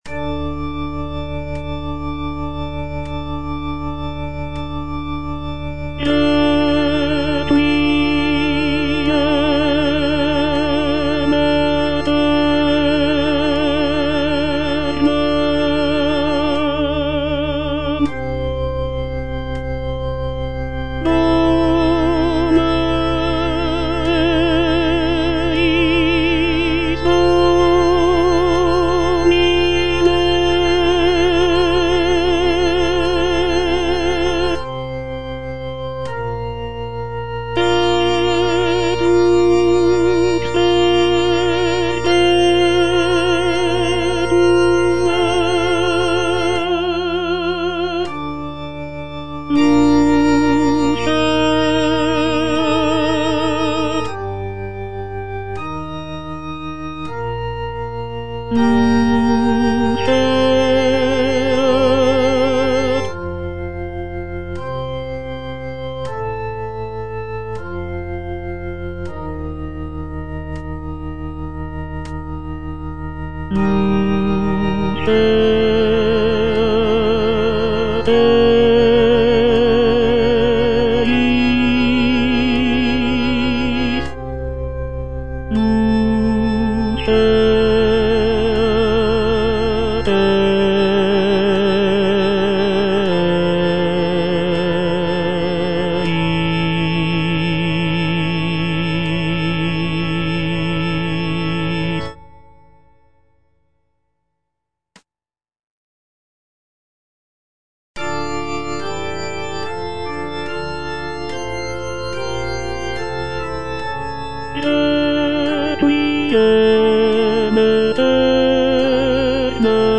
version with a smaller orchestra
tenor I) (Voice with metronome